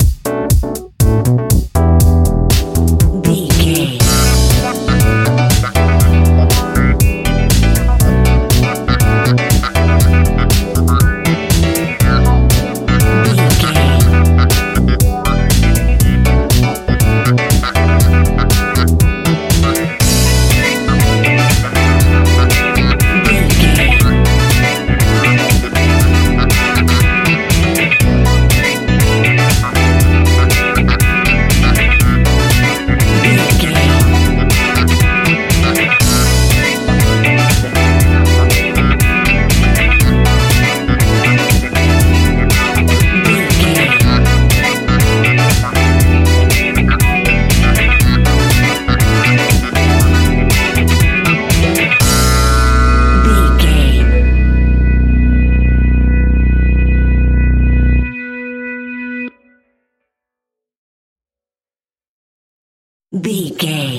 Aeolian/Minor
groovy
futuristic
hypnotic
uplifting
drum machine
synthesiser
funky house
disco house
electronic funk
energetic
upbeat
synth leads
Synth Pads
synth bass